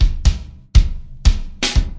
描述：踢圈套循环混响变化120bpm
Tag: 混响 小鼓 循环 现实 试剂盒 鼓组